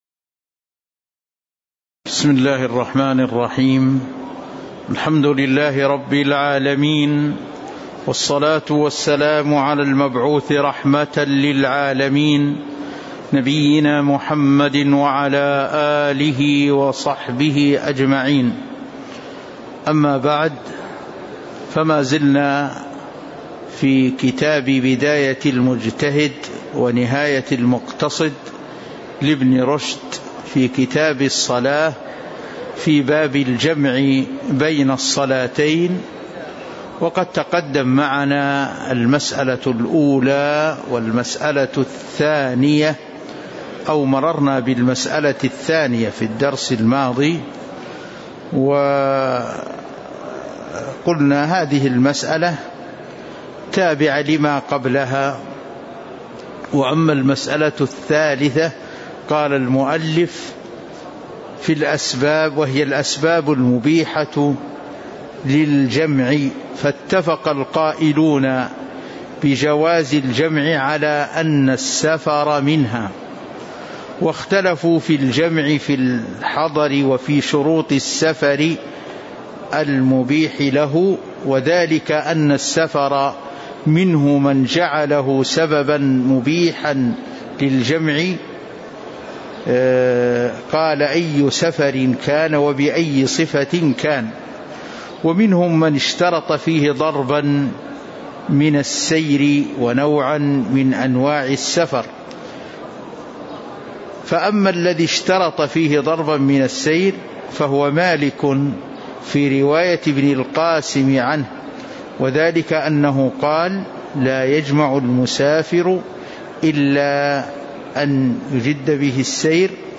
تاريخ النشر ٣ شعبان ١٤٤٣ هـ المكان: المسجد النبوي الشيخ